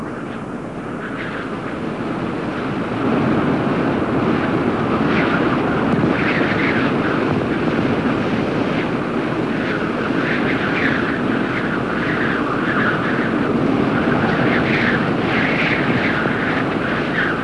Howling Wind Sound Effect
Download a high-quality howling wind sound effect.
howling-wind.mp3